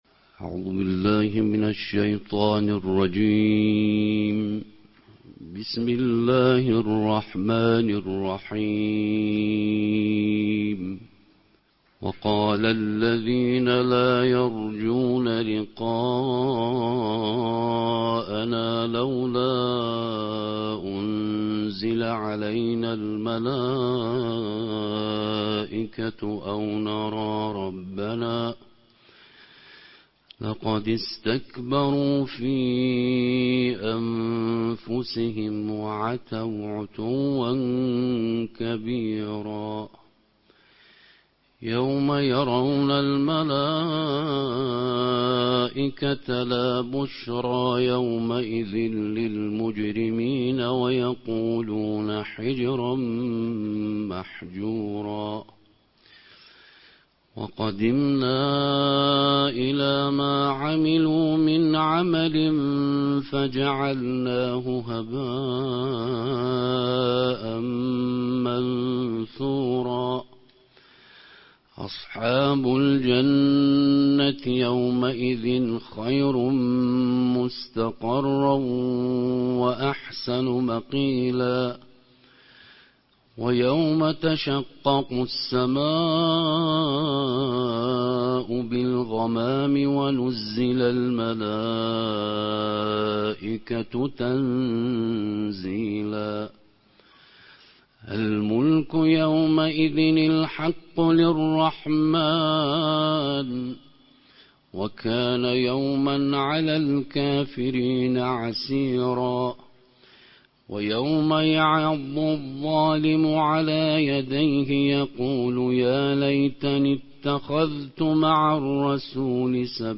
Juzuu ya 19 - Qiraa ya Kila Siku ya Qur’ani